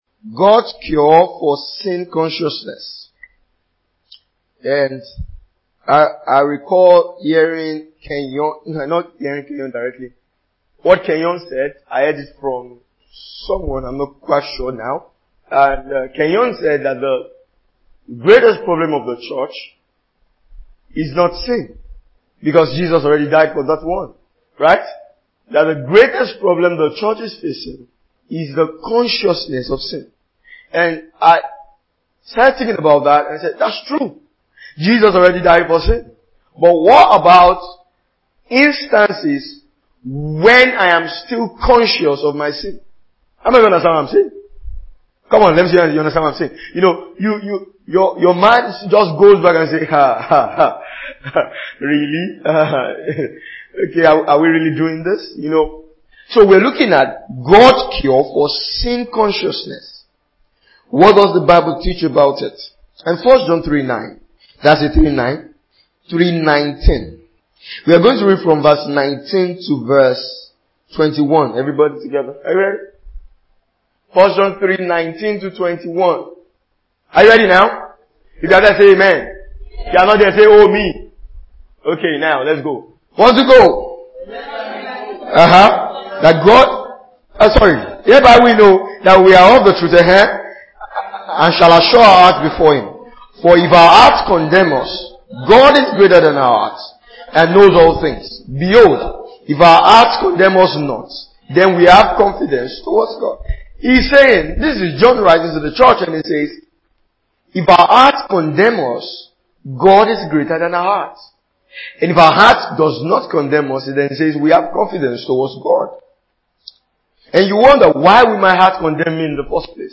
2022 Glorious House Church Teachings.